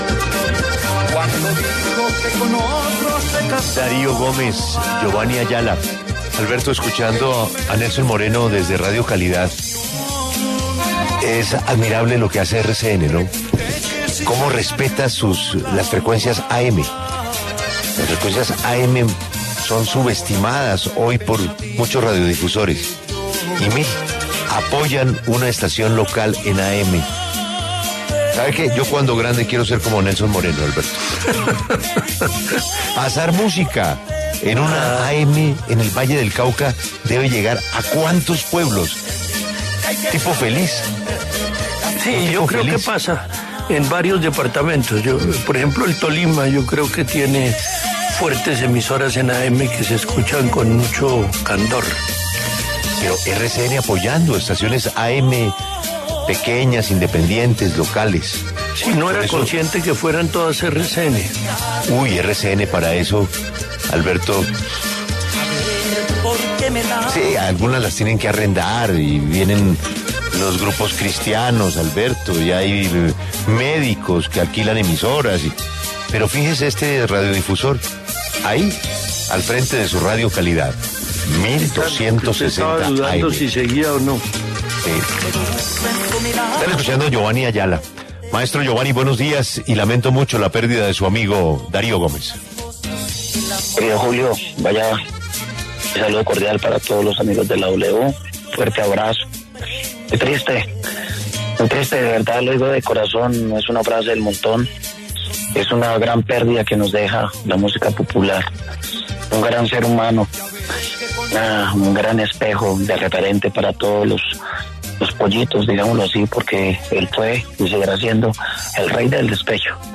Escuche la entrevista completa a Giovanny Ayala en La W: